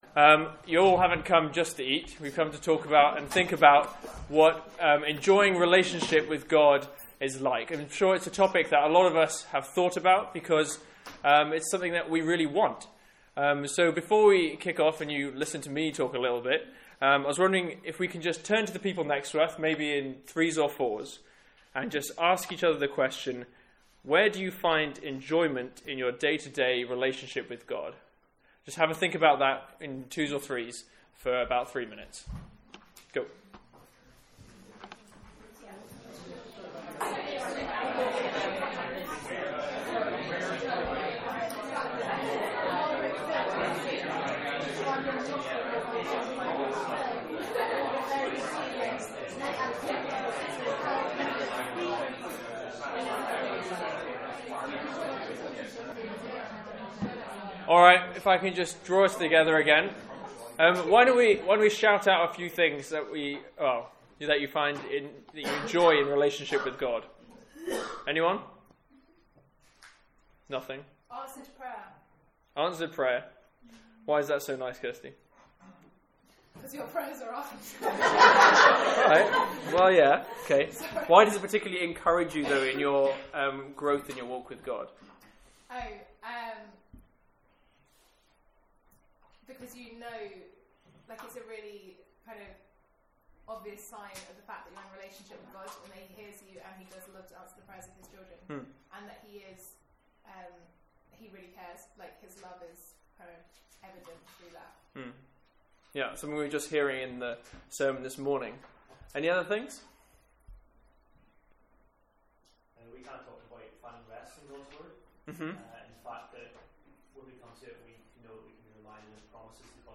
From our student lunch on 3rd December 2017.